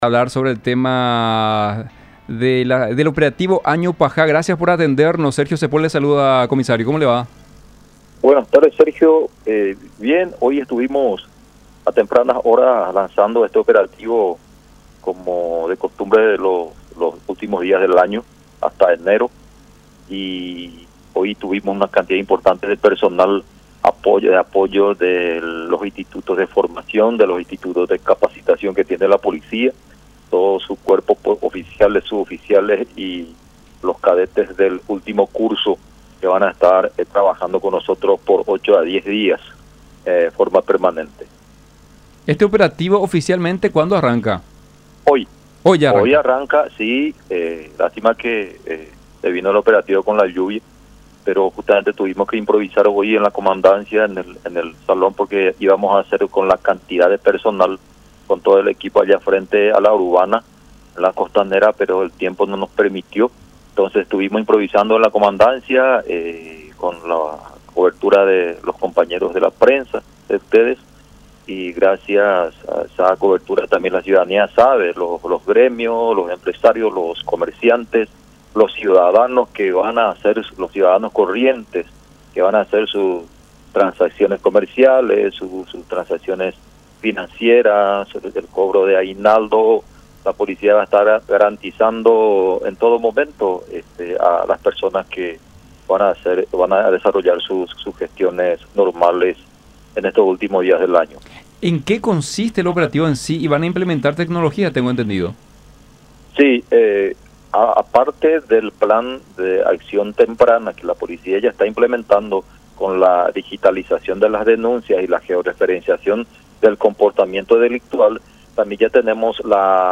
“Como de costumbre los últimos días del año”, expresó en contacto con Unión Informativa.